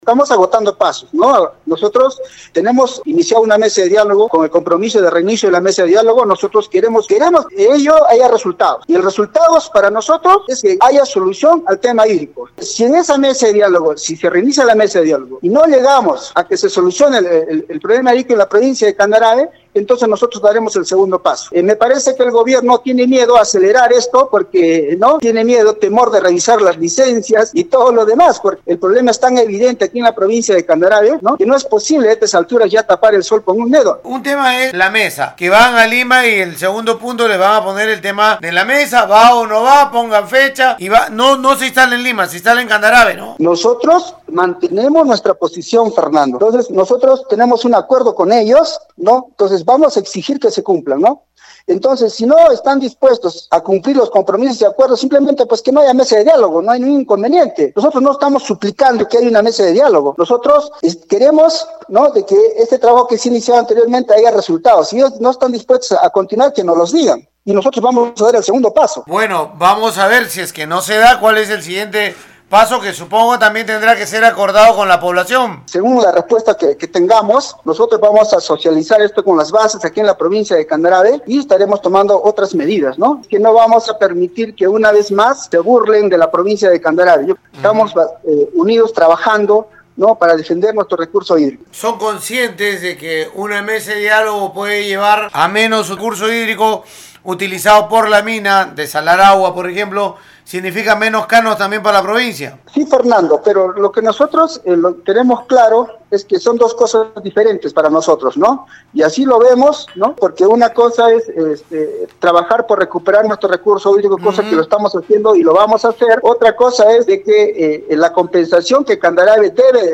rafael-vega-alcalde-de-candarave.mp3